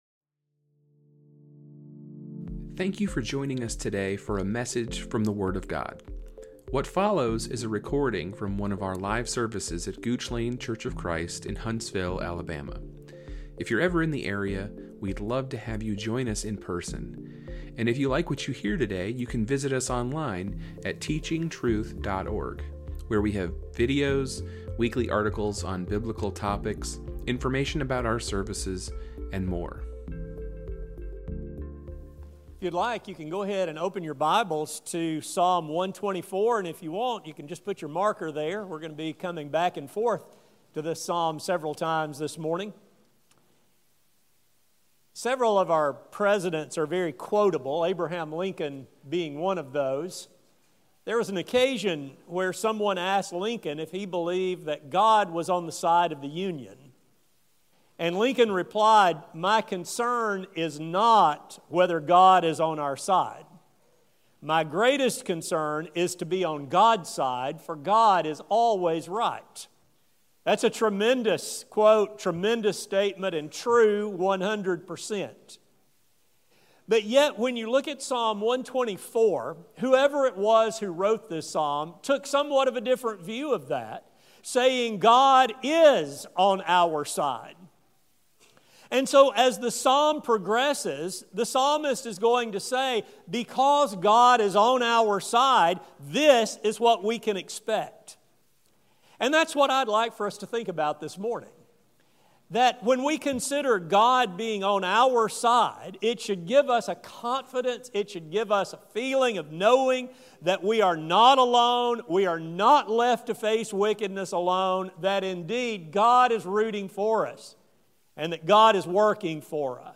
This psalm continues to provide comfort in the present. This sermon will delve into the promises made by God that we can trust Him. It will explore what He has done for our rescue as well as the trust that we can have in Him in times of present battle.